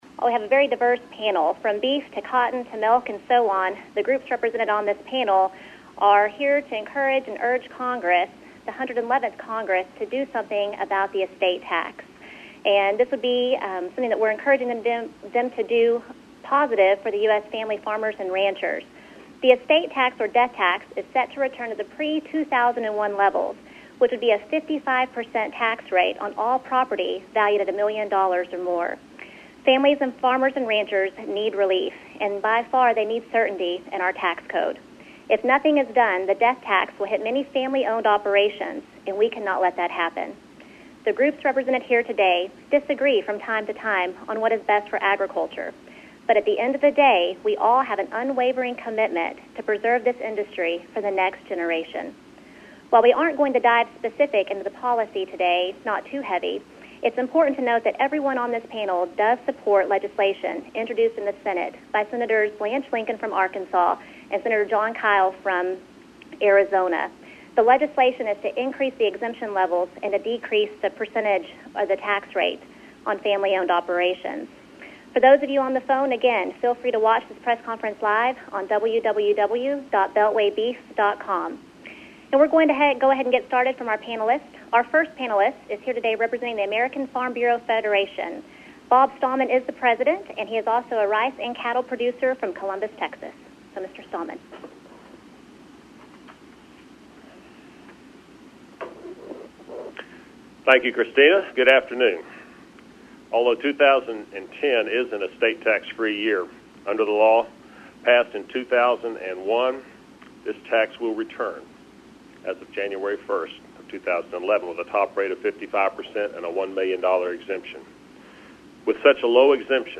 Death Tax Press Conference Audio
In case you were not able to tune in for the estate tax press conference, here is the audio with statements from all the major ag organizations participating. That includes the American Farm Bureau Federation; the American Soybean Association; the National Association of Wheat Growers; the National Cattlemen’s Beef Association; the National Corn Growers Association; the National Cotton Council; the National Farmers Union; the National Milk Producers Federation; the National Pork Producers Council; and the Public Lands Council.